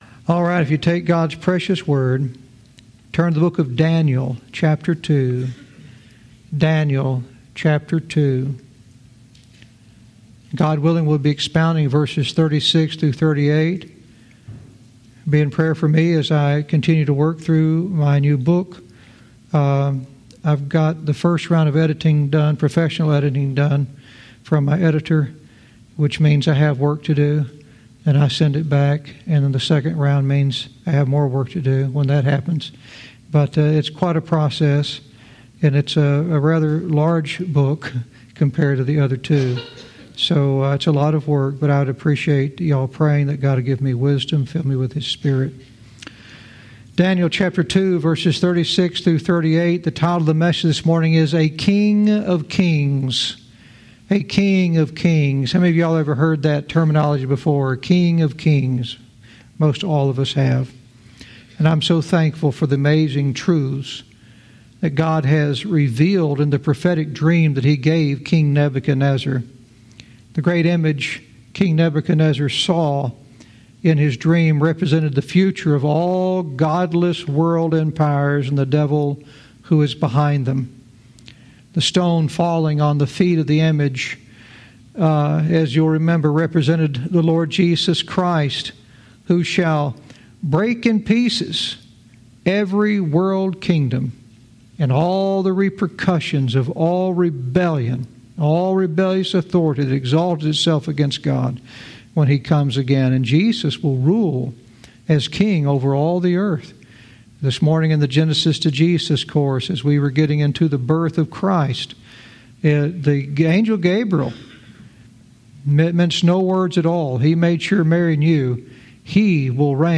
Verse by verse teaching - Daniel 2:36-38 "A King of Kings"